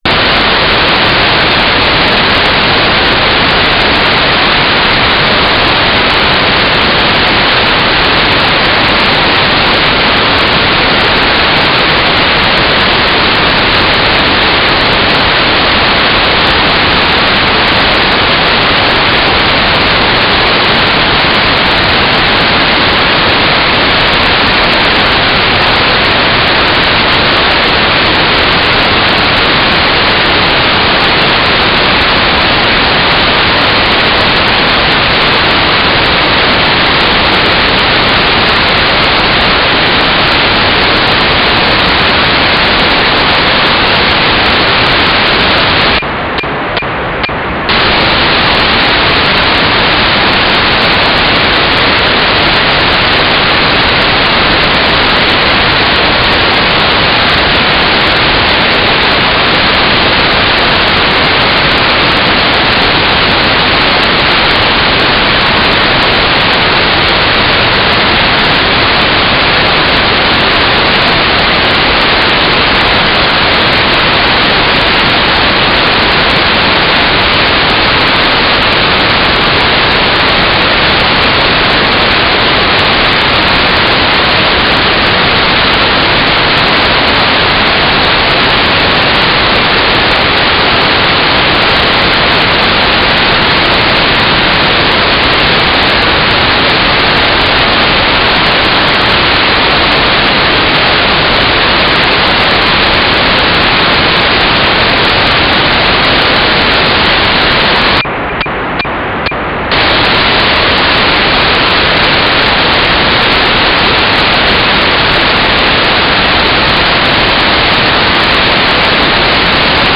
"transmitter_mode": "GMSK",